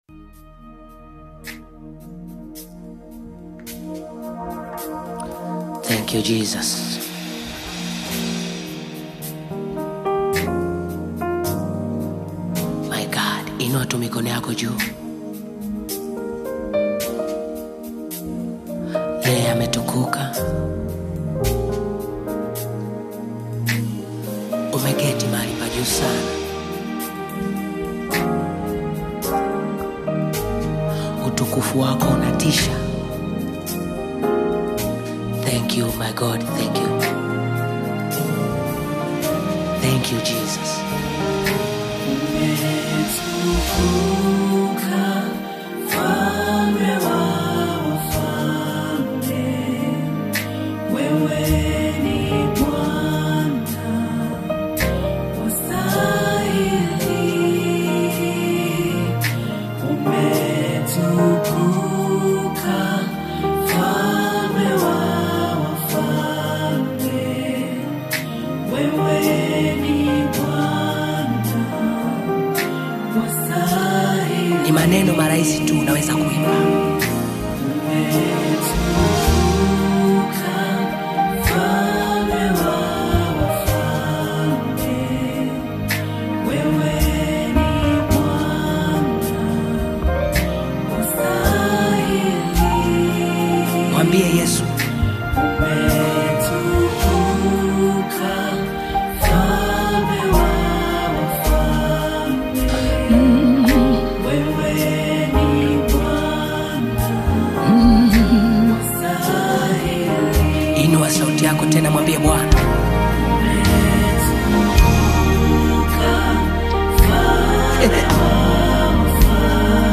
Nyimbo za Dini music
Gospel music track